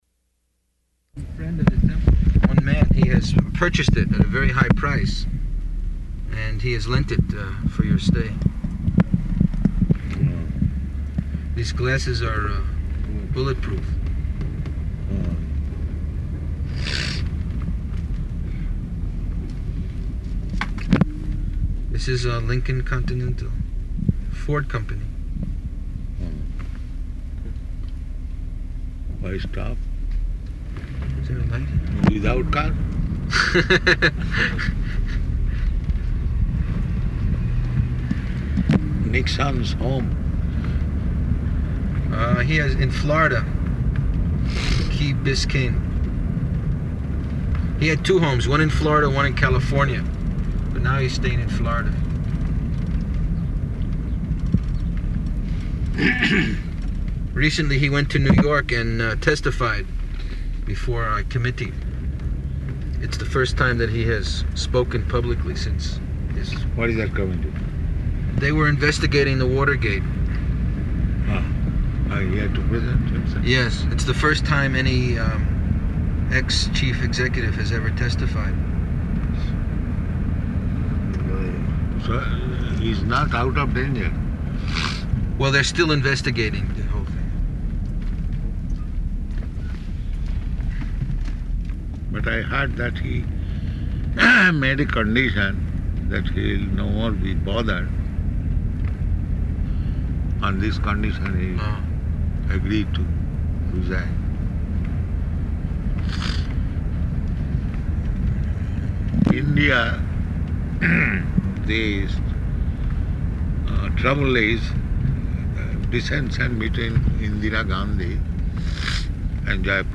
Type: Walk
Location: Denver
[in car]